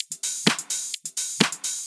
Index of /VEE/VEE Electro Loops 128 BPM
VEE Electro Loop 214.wav